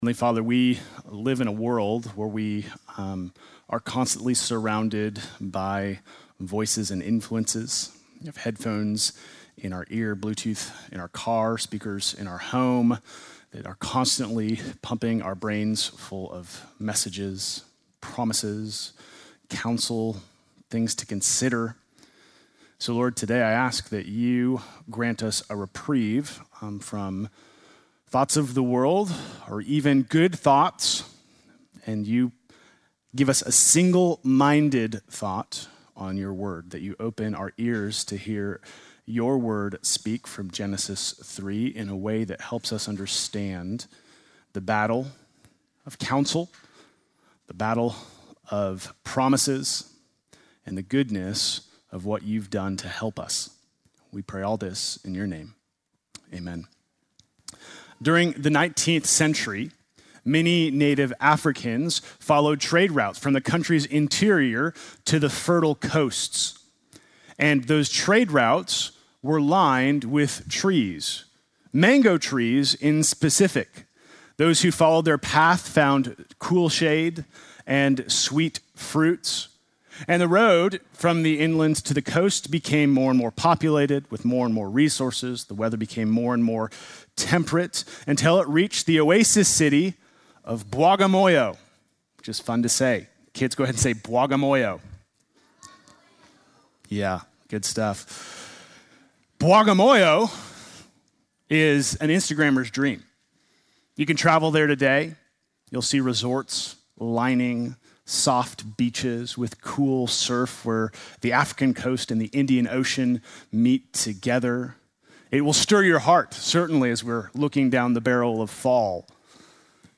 Sunday morning message August 31